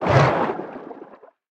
Sfx_creature_squidshark_swimangry_os_03.ogg